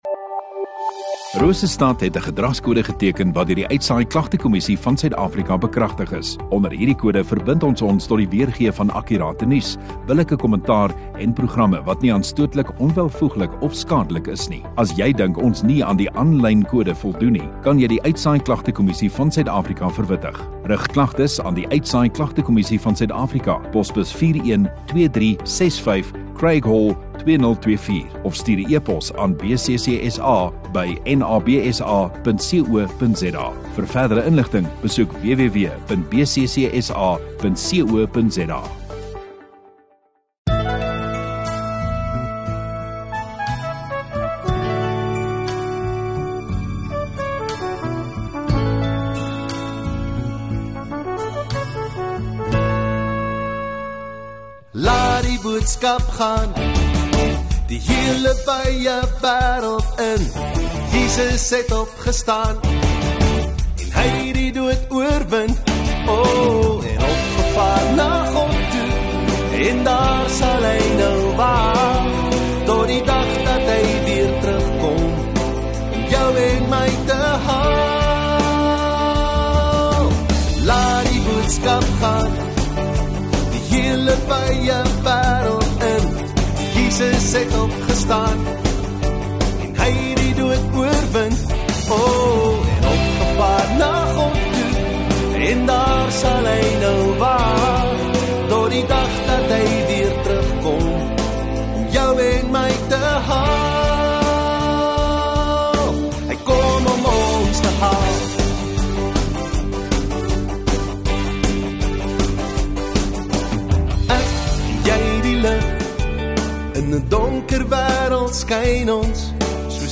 27 Oct Vrydag Oggenddiens